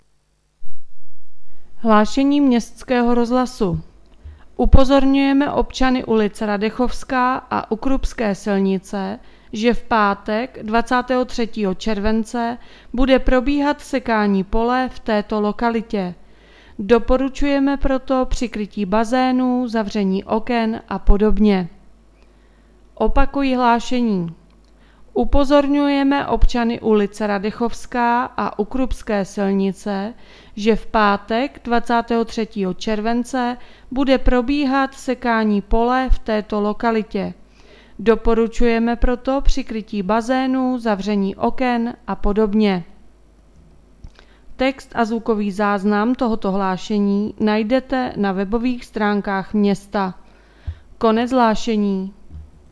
Hlášení městkého rozhlasu 22.7.2021